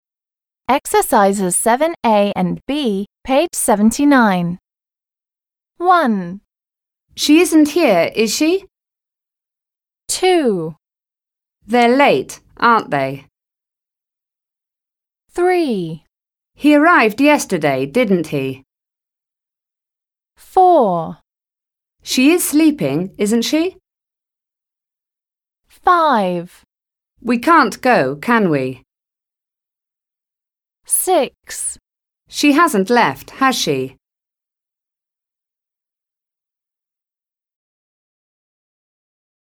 Listen and decide if the intonation is rising or falling.